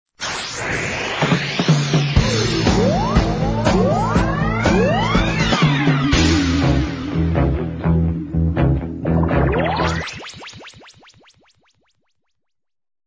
The recycled jingle collection: